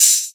• Open Hat A Key 15.wav
Royality free open hi hat one shot tuned to the A note. Loudest frequency: 7156Hz
open-hat-a-key-15-ski.wav